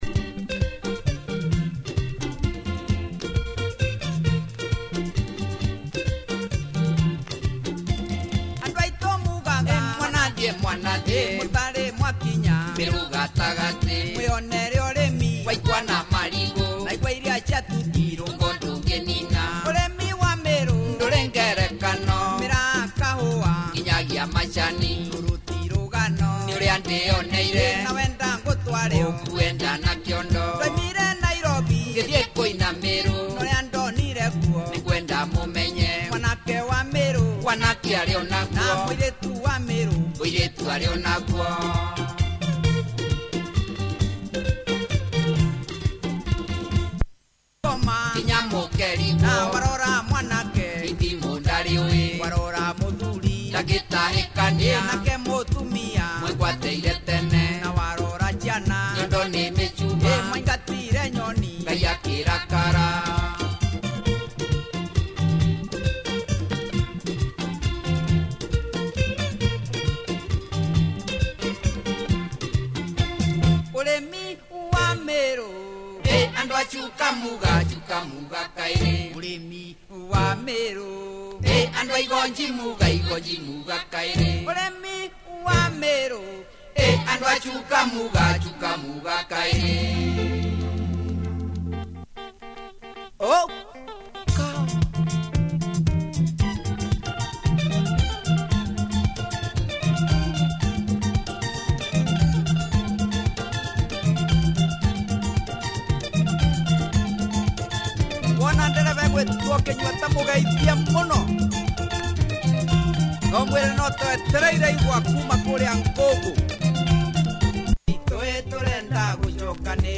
Kikuyu Benga vocal harmony